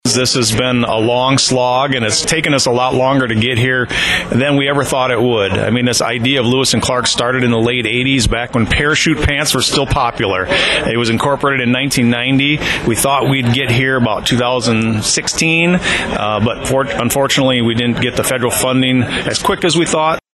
Sioux Center and Hull had been waiting for more than 30 years for their hookups to the system. They celebrated Tuesday with ribbon cuttings